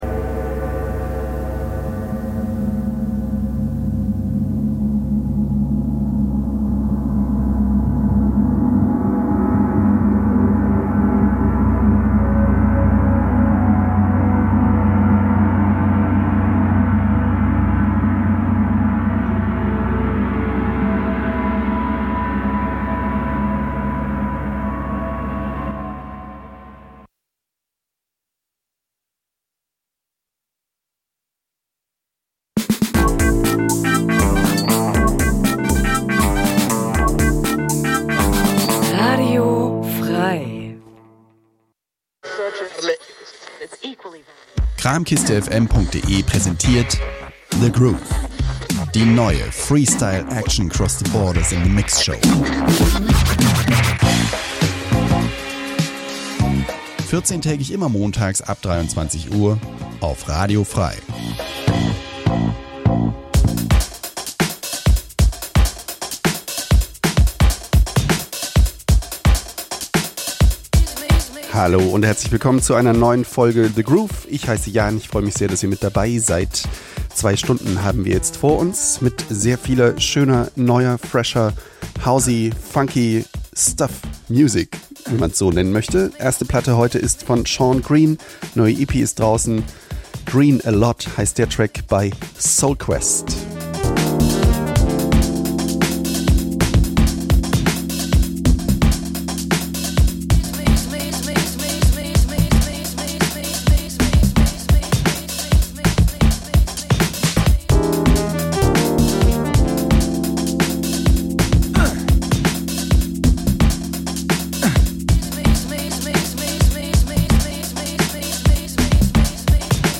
Experimental, Dub, Electronica, etc
House, Drum�n�Bass, Breaks, Hip Hop, ...